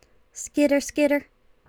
infinitefusion-e18 / Audio / SE / Cries / SPINARAK.wav